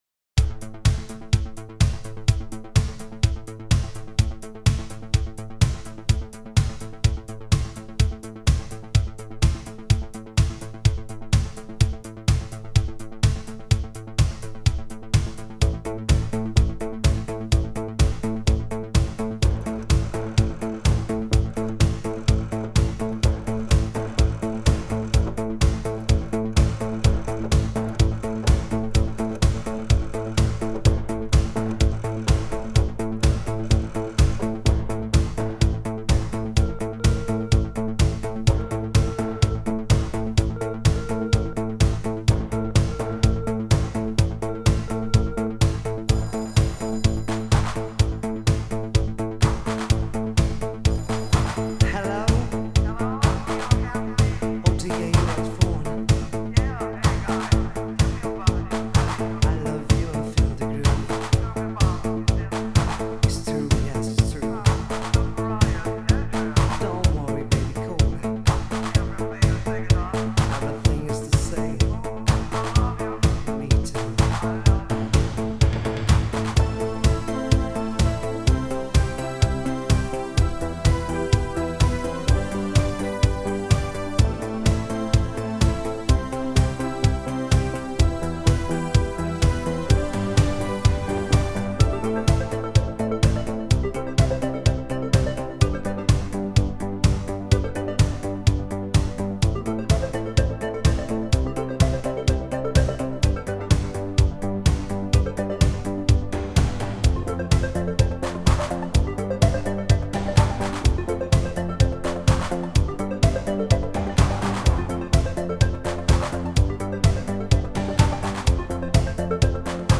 - это уже классический евробит